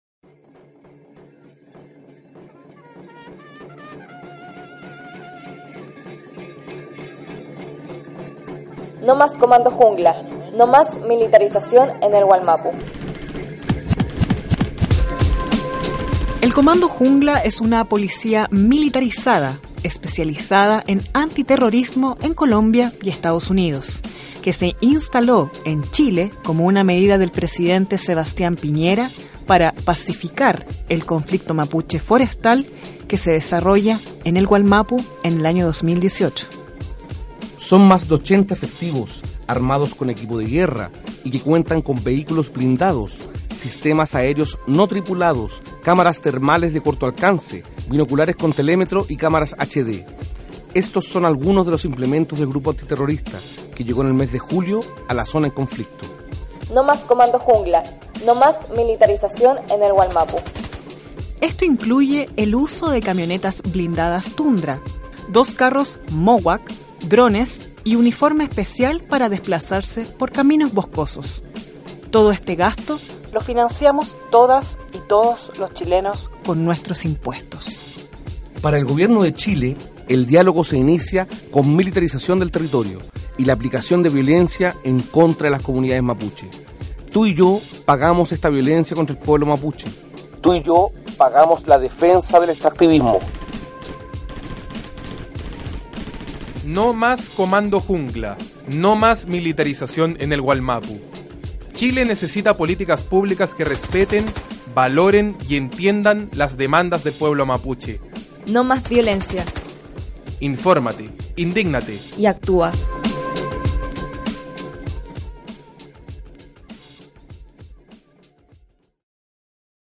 A continuación, extendemos la campaña radial: «NO + Comando Jungla», material radiofónico de concientización sobre la violencia y el dolor que la policía militarizada está dejando en las comunidades Mapuche en Wallmapu.